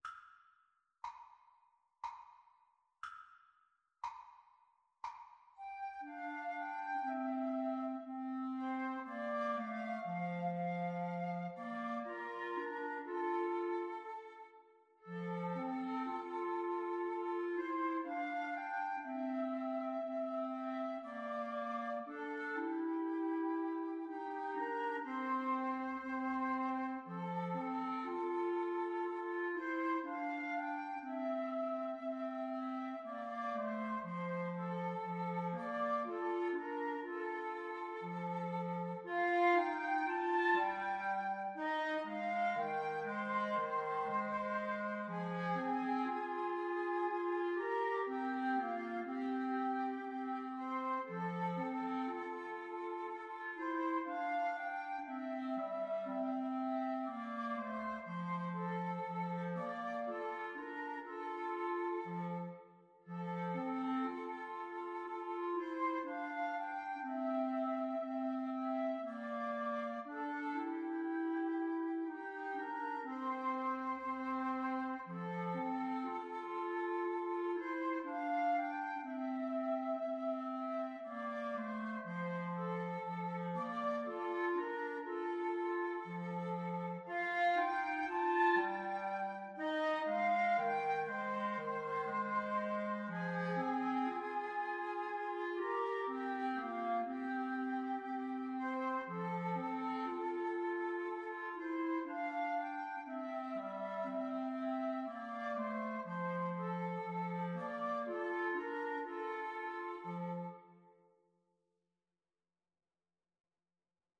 3/4 (View more 3/4 Music)
Slow, expressive =c.60